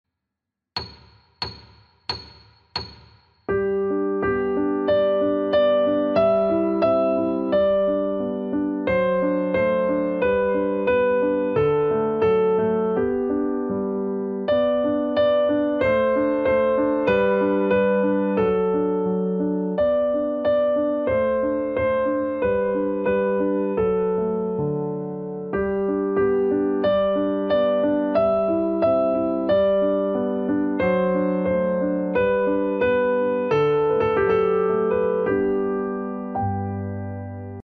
Twinkle twinkle na klavír - videolekce a noty pro začátečníky
14-Twinkle-twinkle-vysledek-rozlozene-metronom.mp3